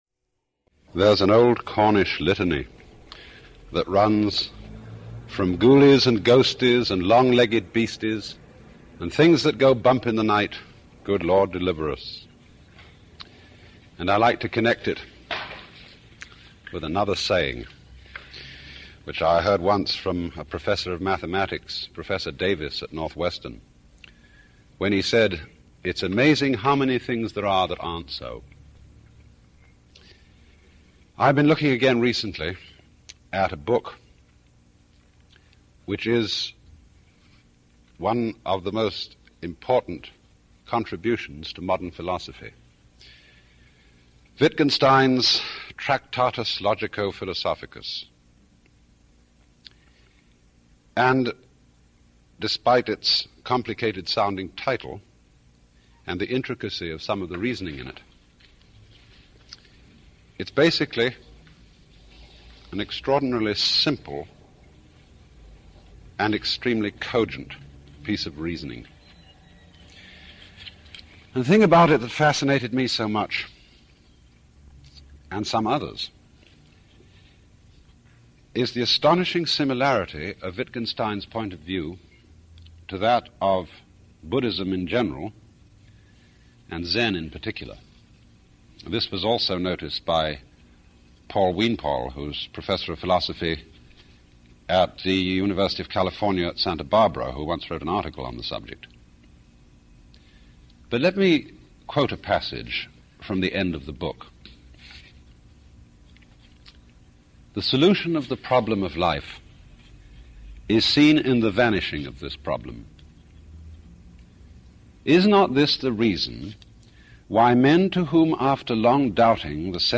Alan Watts – Early Radio Talks – 11 – Ghosts